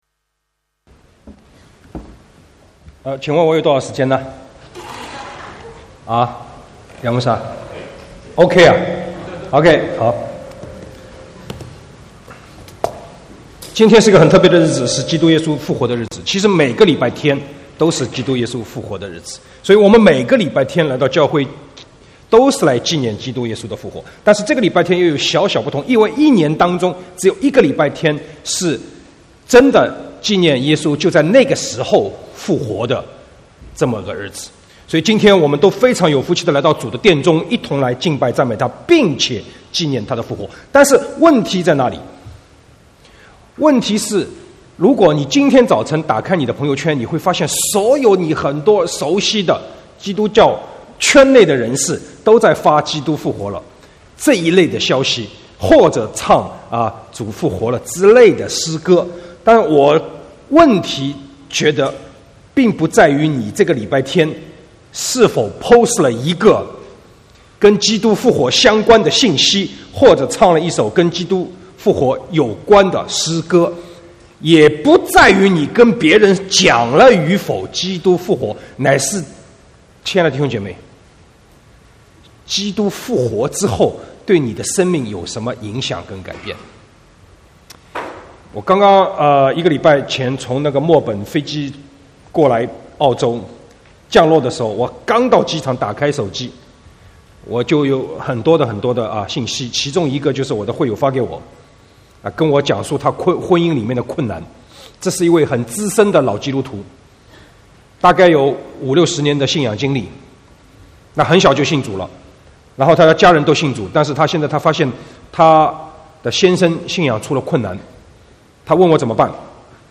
1/4/2018 國語堂講道